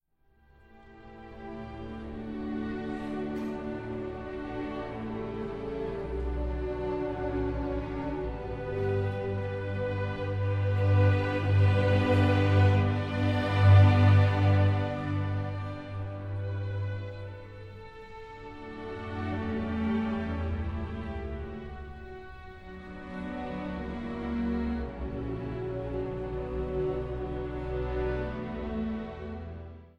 Sopran I
Tenor